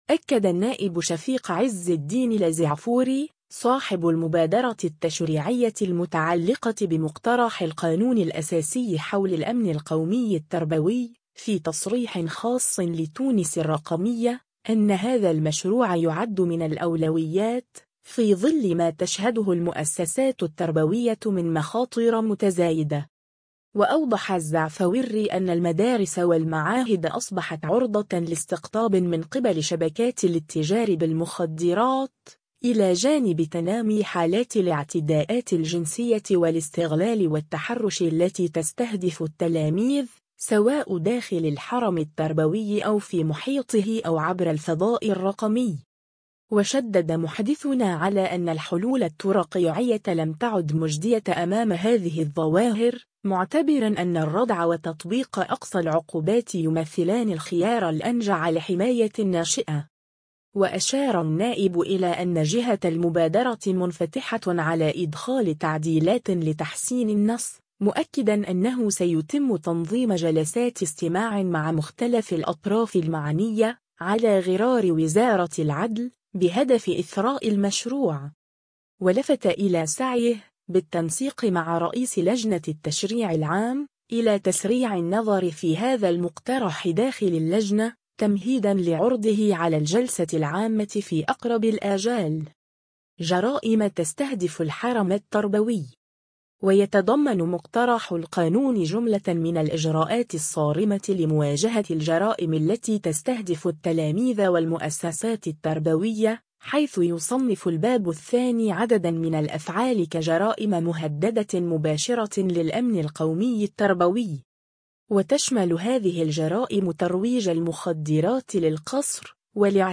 أكد النائب شفيق عز الدين الزعفوري، صاحب المبادرة التشريعية المتعلقة بمقترح القانون الأساسي حول الأمن القومي التربوي، في تصريح خاص لـ”تونس الرقمية”، أن هذا المشروع يُعد من الأولويات، في ظل ما تشهده المؤسسات التربوية من مخاطر متزايدة.